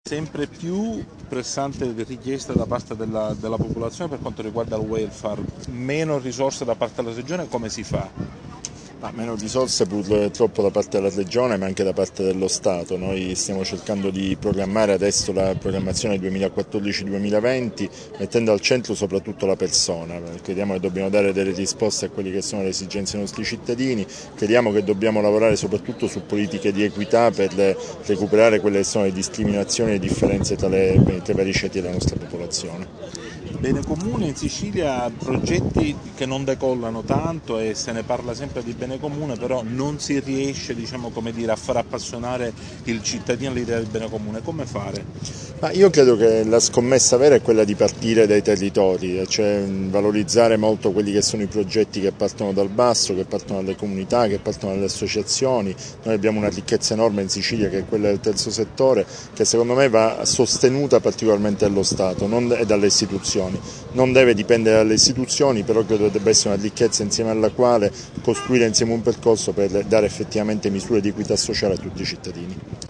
[ASCOLTA QUI L’INTERVISTA] ha evidenziato come «il quadro normativo che vige in Sicilia è da rivedere» ha detto, illustrando, altresì, gli interventi che, con poche risorse disponibili, la Regione sta attuando.
assessore_bruno.mp3